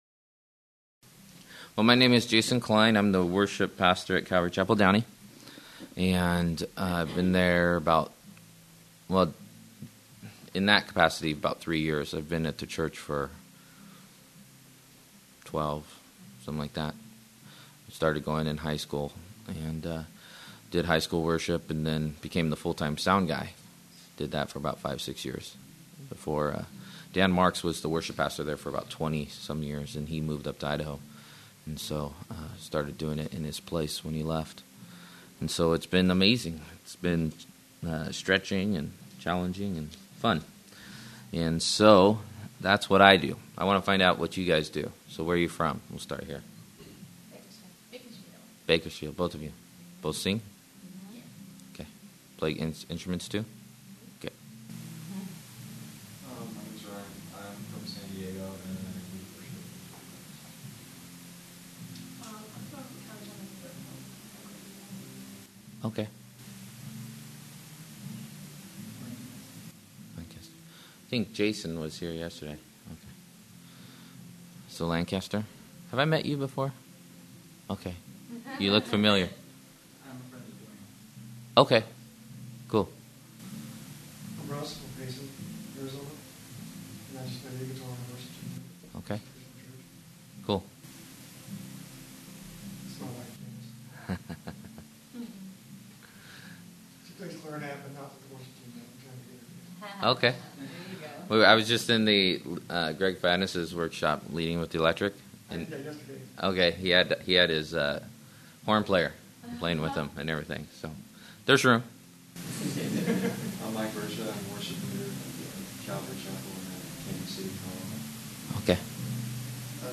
Series: 2005 Calvary Chapel Worship Leader Conference
Campus: Calvary Chapel Costa Mesa
Service Type: Workshop